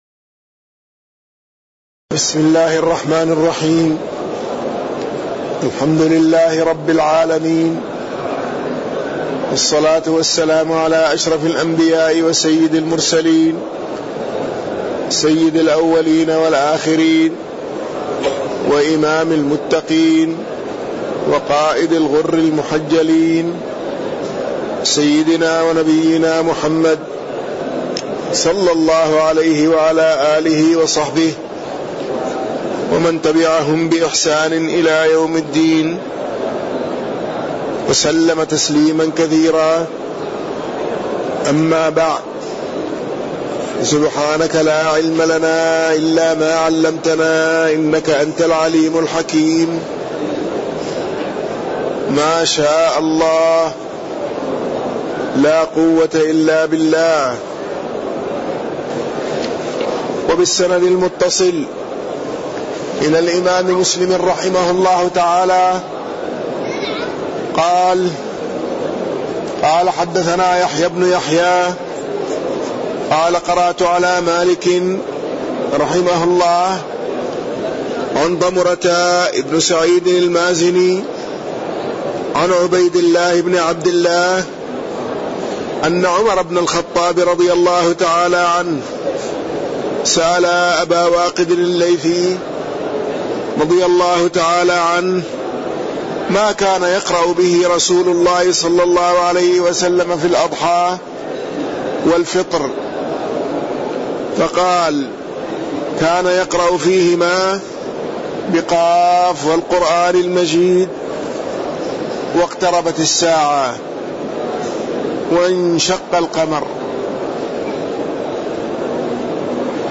تاريخ النشر ٢٣ رمضان ١٤٣١ هـ المكان: المسجد النبوي الشيخ